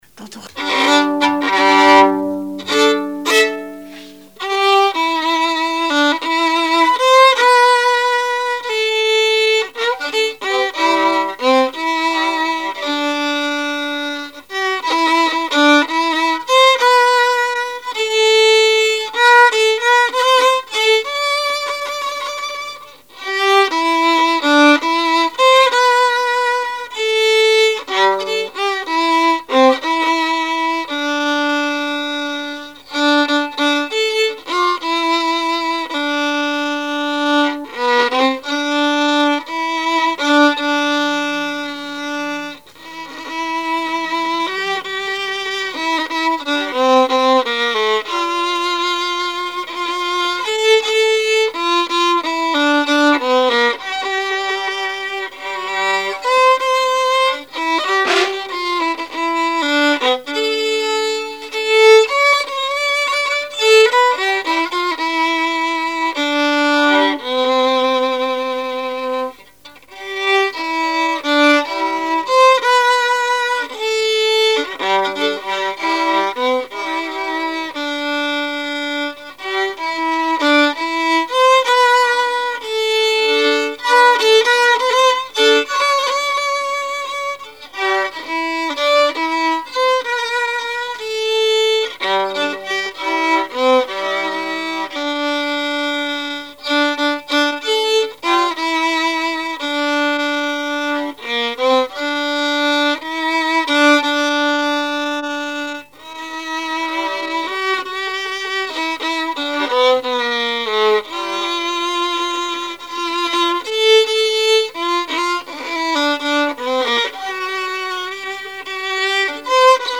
musique varieté, musichall
Saint-Christophe-du-Ligneron
Genre strophique
Répertoire musical au violon
Pièce musicale inédite